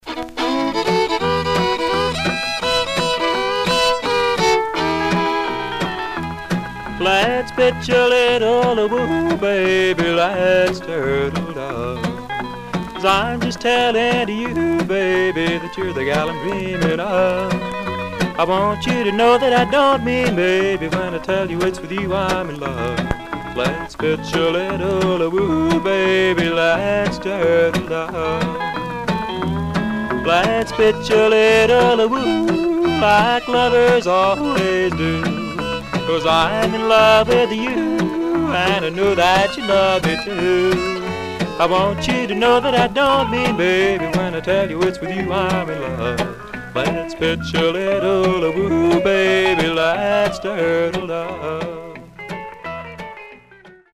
Some surface noise/wear Stereo/mono Mono
Country